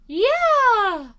peach_yahoo4.ogg